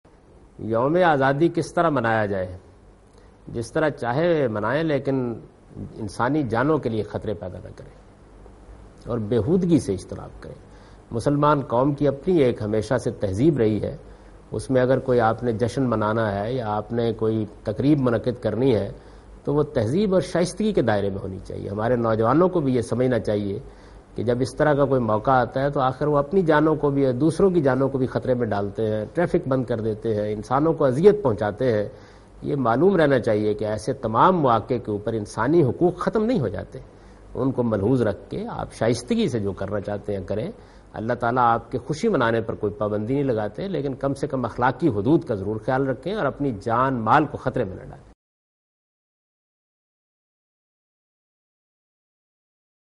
Category: English Subtitled / Questions_Answers /
Javed Ahmed Ghamidi replying a question about celebrating Independence Day.